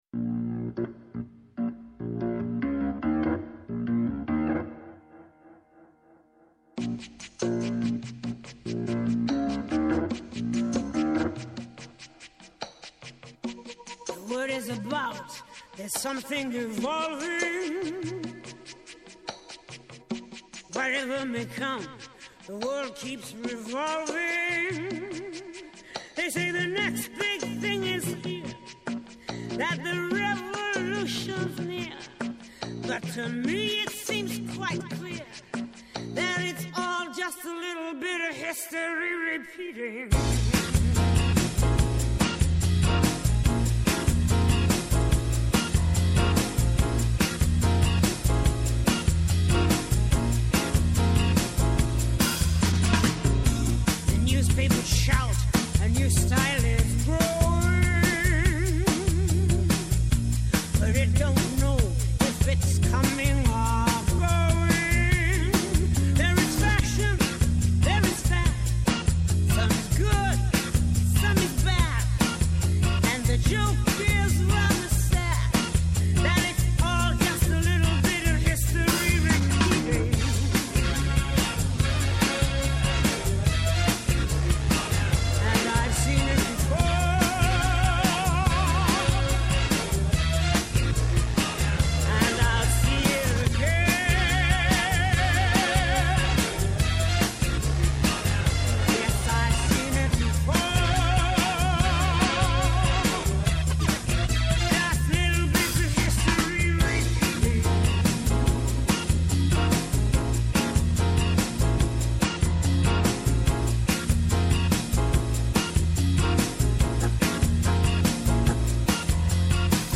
-Η Δόμνα Μηχαηλίδου, Υπουργός Εργασίας
-Ο Νίκος Φαραντούρης, πολιτικός ΣΥΡΙΖΑ
Συνεντεύξεις